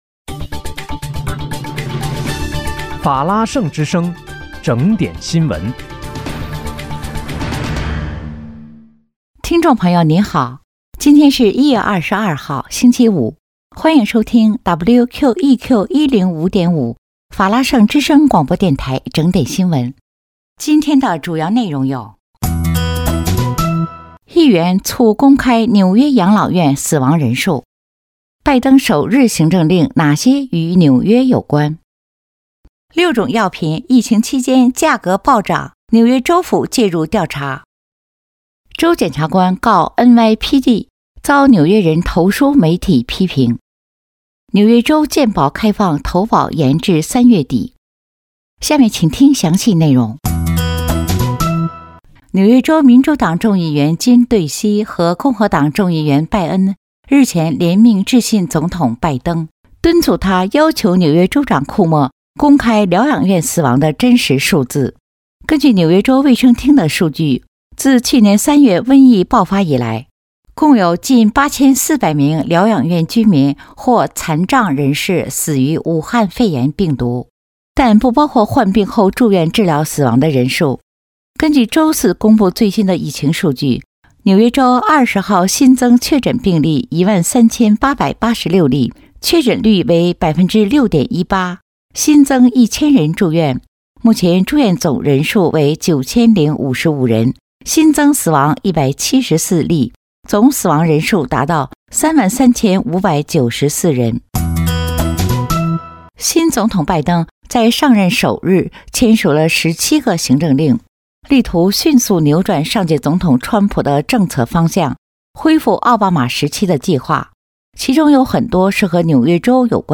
1月22日（星期五）纽约整点新闻